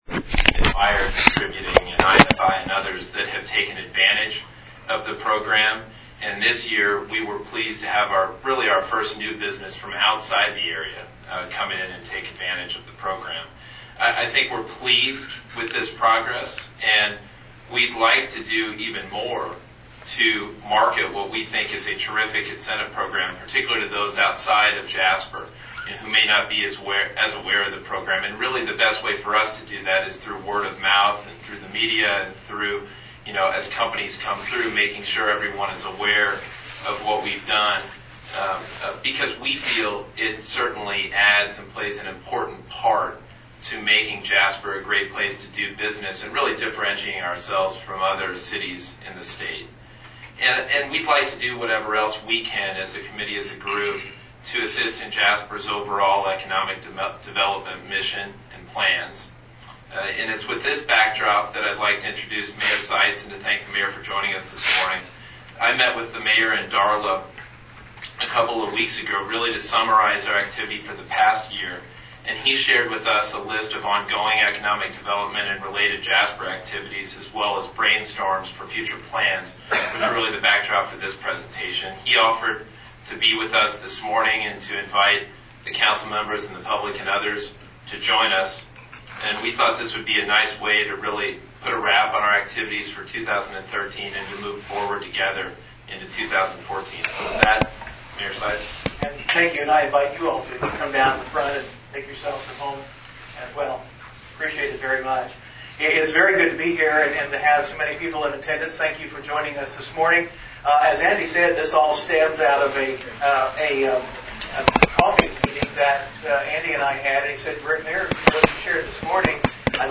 Jasper — About 65 people filled the Jasper Council Chambers to hear a presentation by Mayor Terry Seitz during the Economic Development Commission meeting held Thursday morning. Seitz’s presentation touted the city’s current projects and plans as well as introduced some new ideas and concerns. Of note was the mayor’s mention of a potential parking garage being added to city-owned land northeast of the post office at the corner of Mill and Sixth Street.
Mayor-Seitz-presentation.mp3